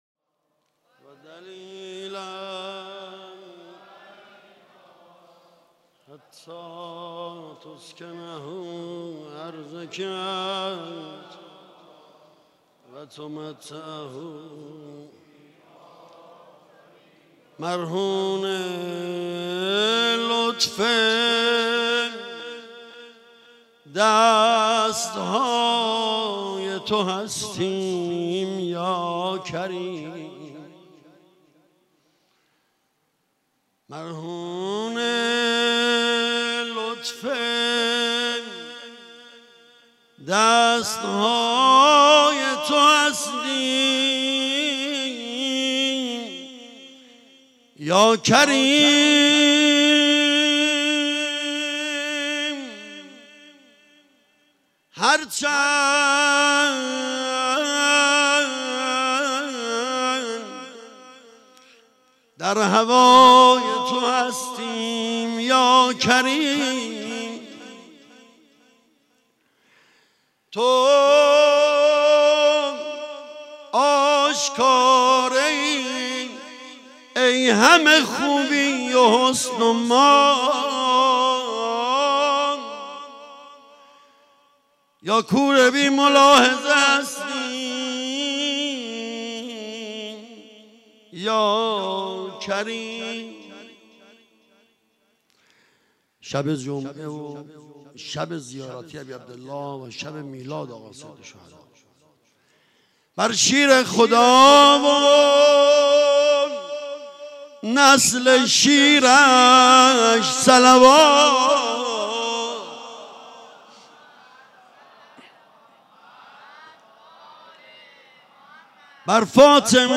مراسم جشن میلاد امام حسین
مداحی
در مسجد حضرت امیر(ع) برگزار شد.